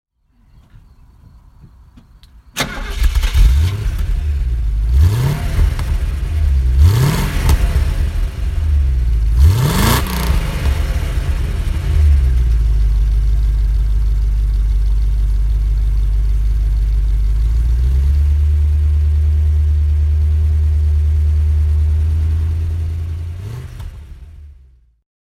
Mercedes-Benz 450 SLC 5.0 (1978) - Starten und Leerlauf